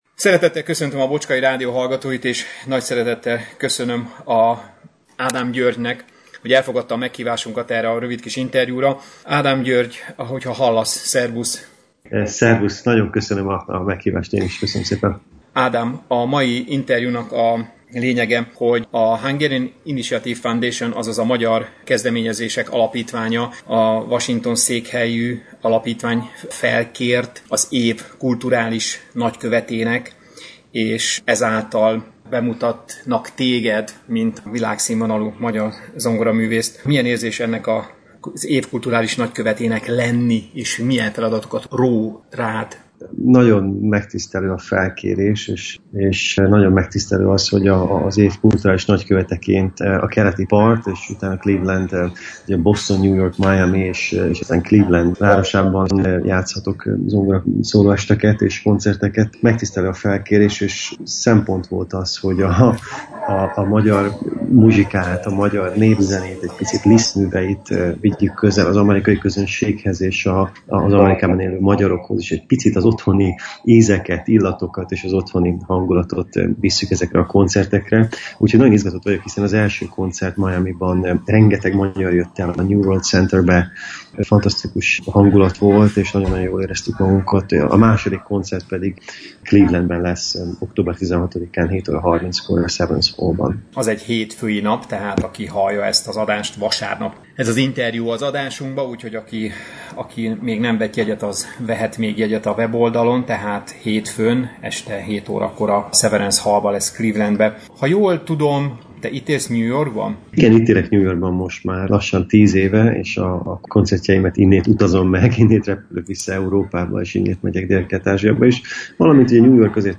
Az október 16-án, hétfőn este, 7:30-kor kezdődő előadásáról beszélgettünk György Ádámmal szerdán este telefonon, aki elmondta izgatottan várja a clevelandi fellépését, ugyanis sosem járt még városunkban, sem a méltán híres Severance Hallban.